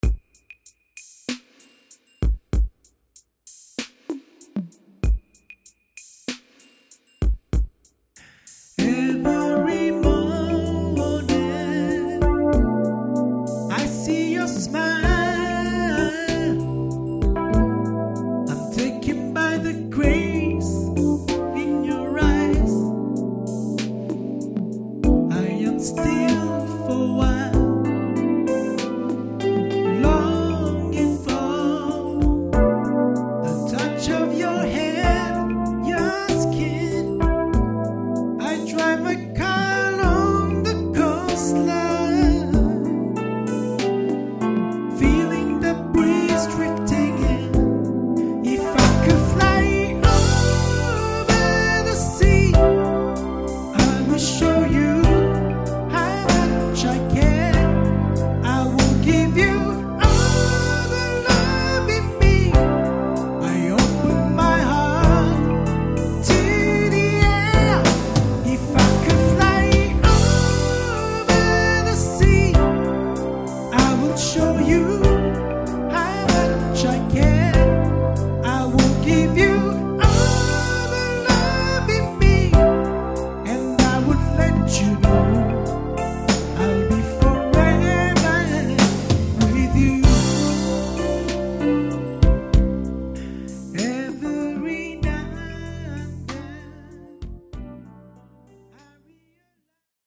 This was an experimental work of 24 bit recording using M-Audio Firewire Solo audio interface.
Vocals
Gear: Yamaha S90